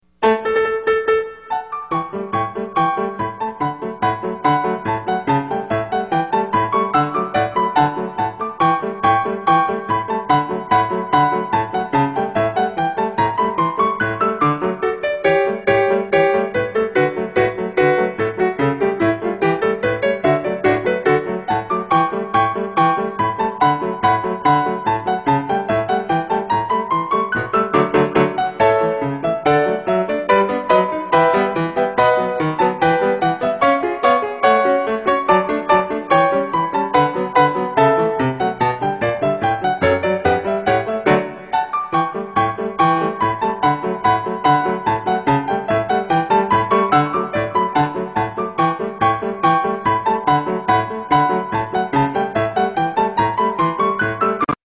Galop
Dance Through Time5 Galop.mp3